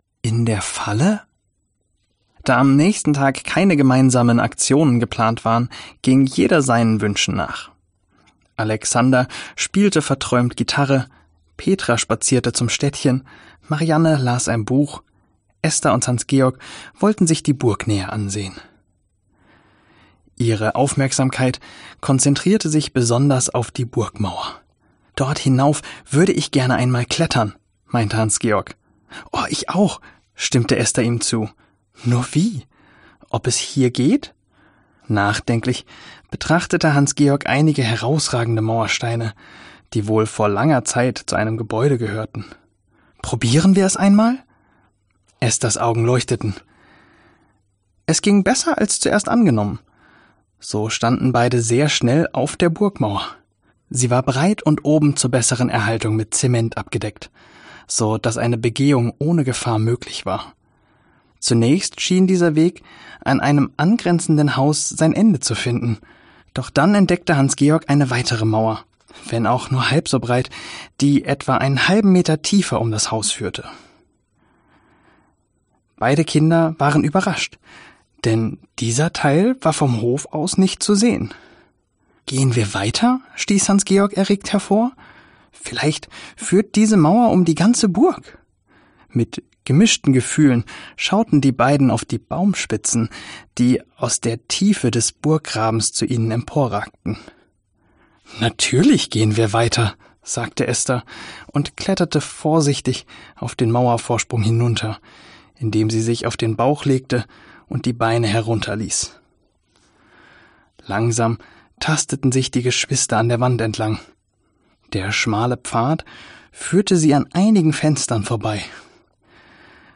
Genre : Hörbuch